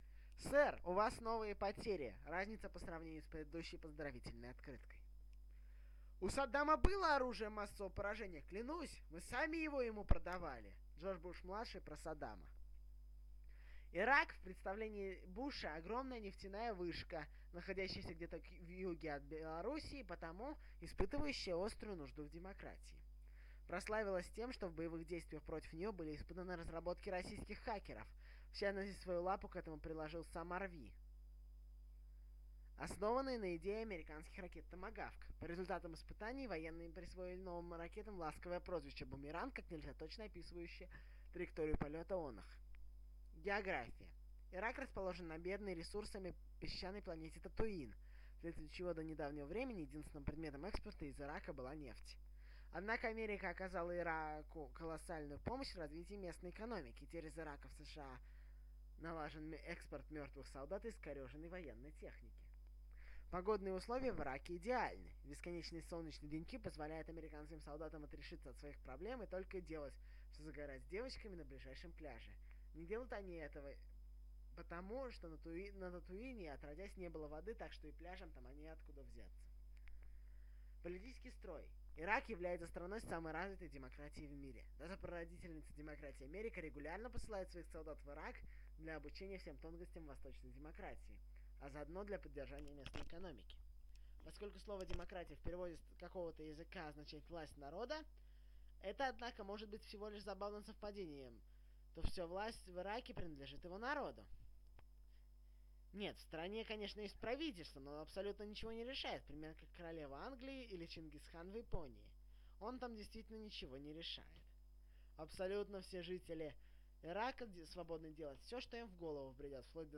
Аудиозапись статьи "Ирак".